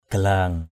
/ɡ͡ɣa-la:ŋ/ 1.
galang.mp3